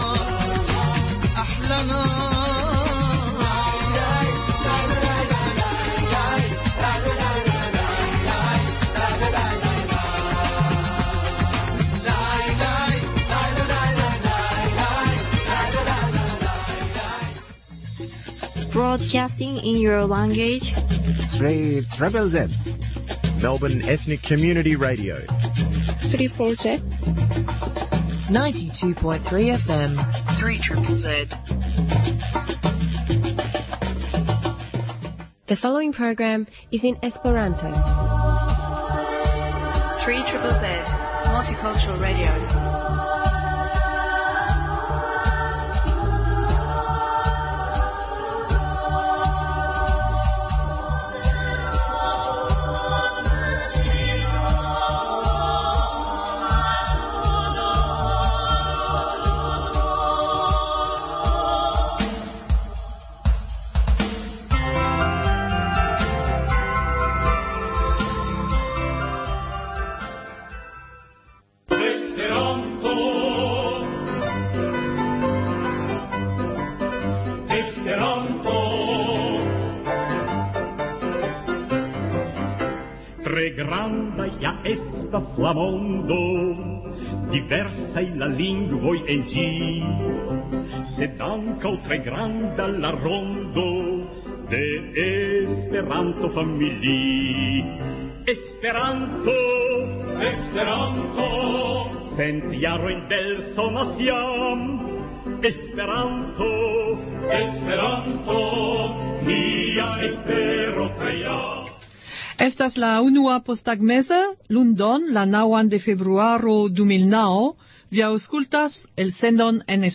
Intervjuo